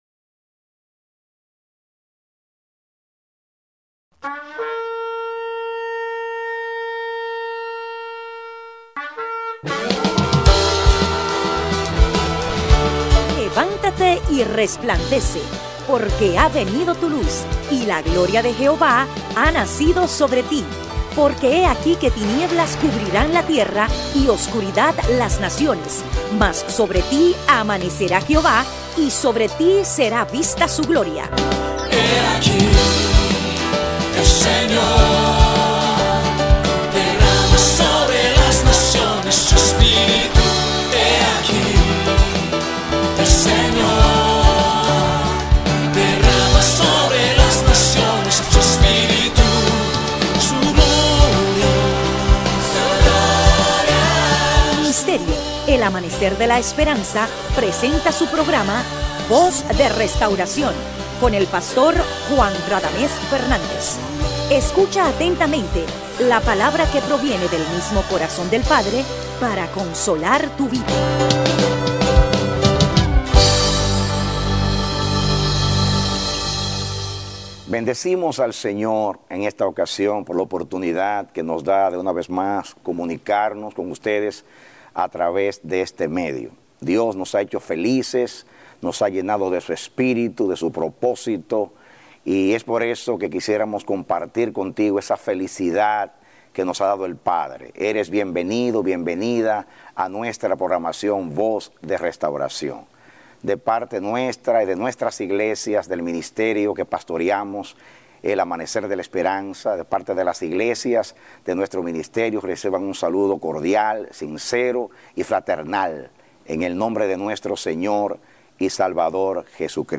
A mensajes from the series "Conociendo a Dios."